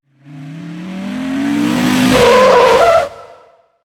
Frenazo de un coche